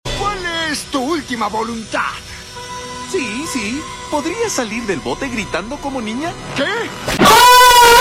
Salir Gritando Como Niña